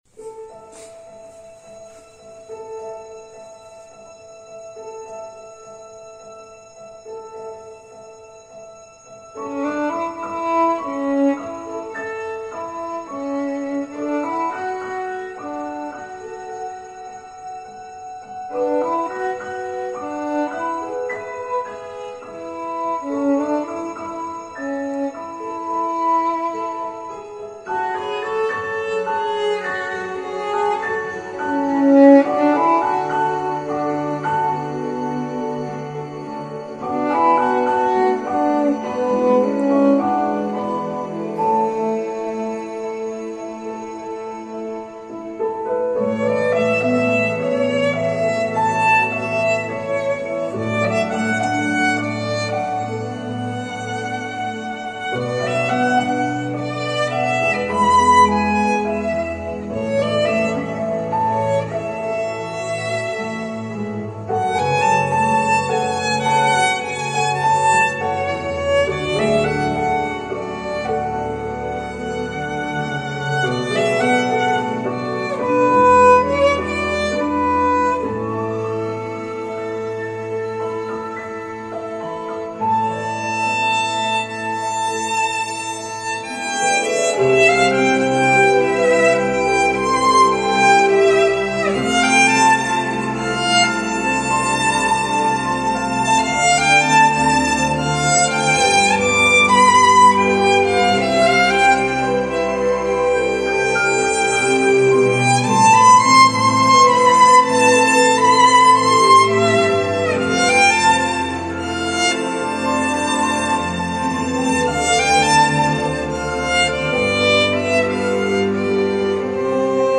Violine Solo (mit Playback)